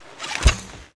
archer_attk_a.wav